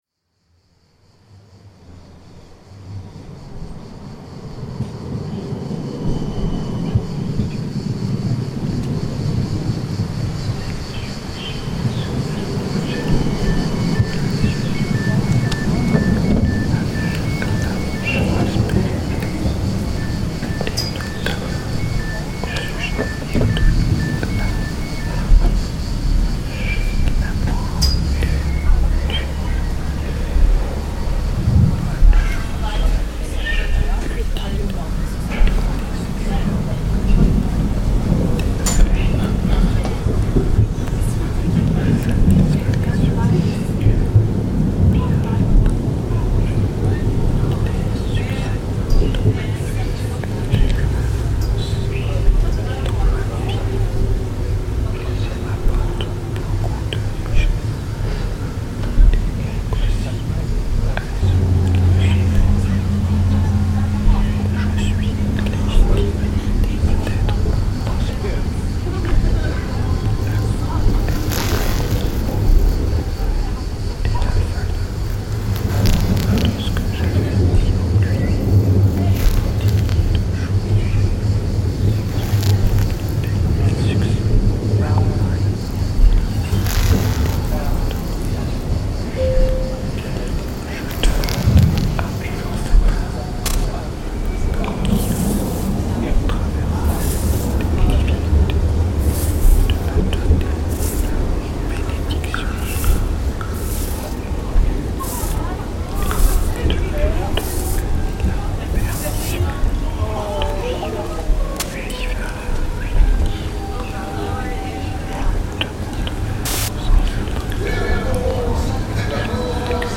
Tram ride, whispers, beeps
I tried to give "shape," or rather "sound," to what the voices and noises of the tram produced, what they triggered within me. I followed the rhythm; an imaginary flow, somewhat haunted or at least inhabited, took shape along the length of the original file."
Oslo tram ride reimagined